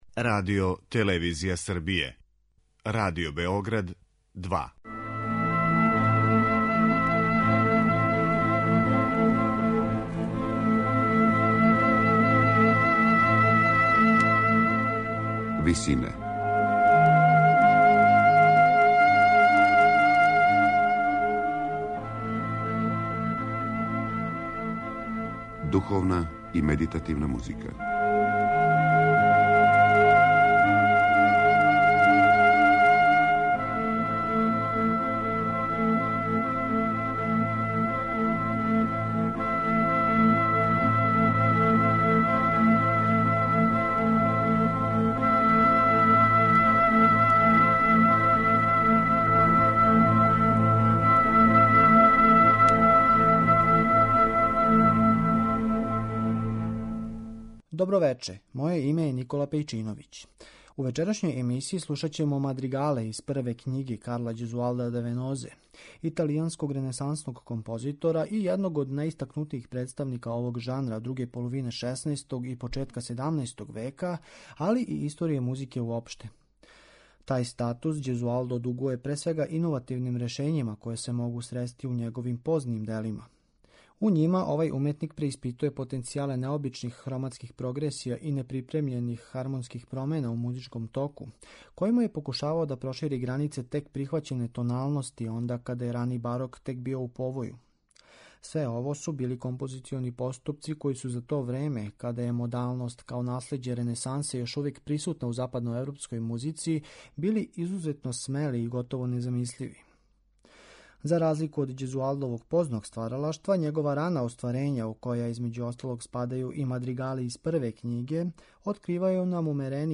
Мадригали Карла Ђезуалда да Венозе